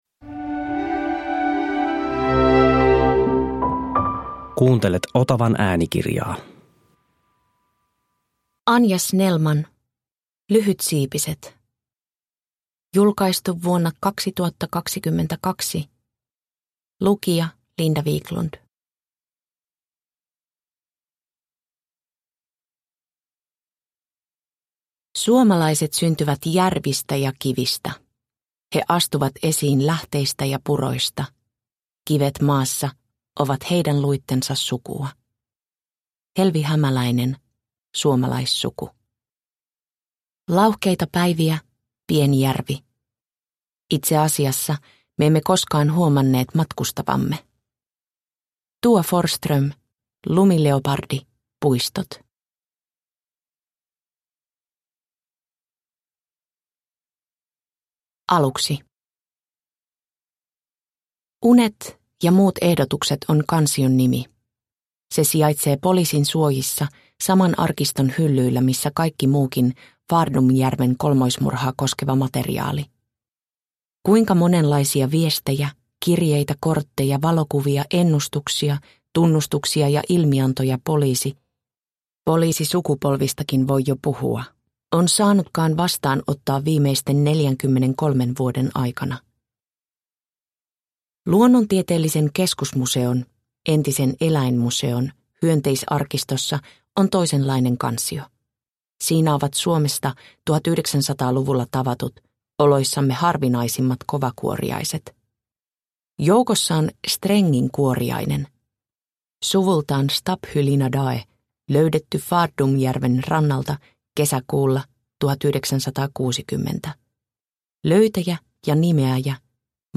Lyhytsiipiset – Ljudbok – Laddas ner